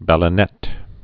(bălə-nā)